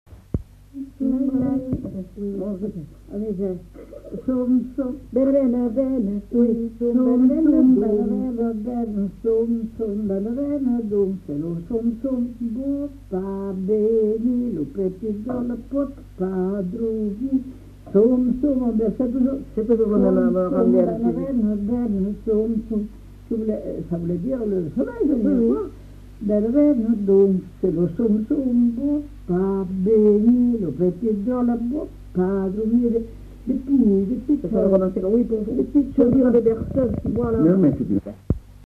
Aire culturelle : Marsan
Genre : chant
Effectif : 1
Type de voix : voix de femme
Production du son : chanté
Classification : som-soms, nénies